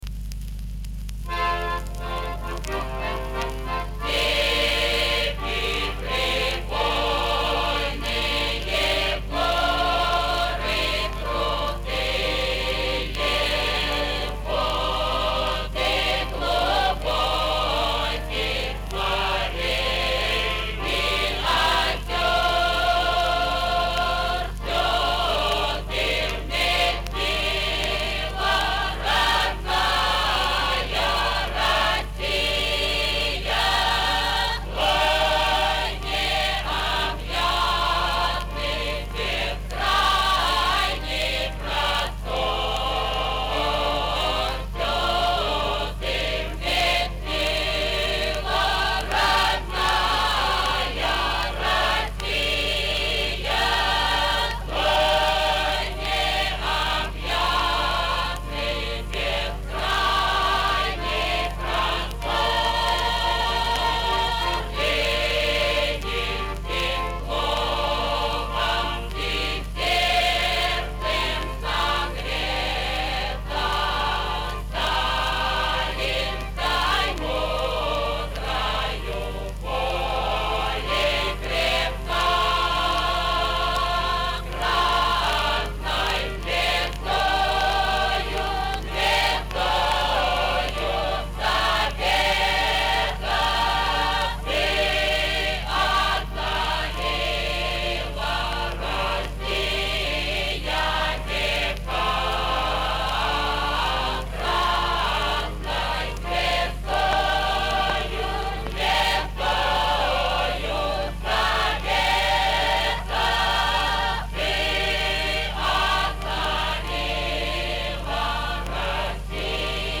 Народная песня.